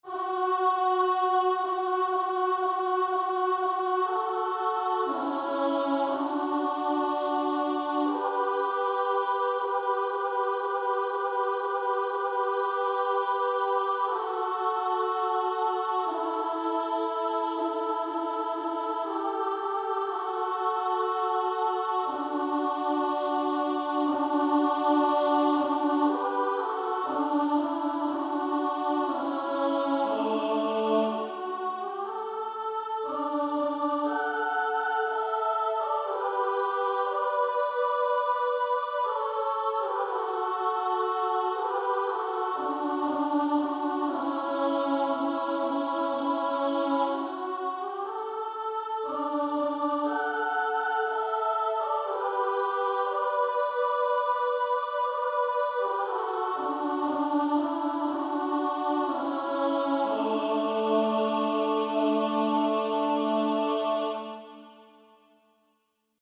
Practice then with the Chord quietly in the background.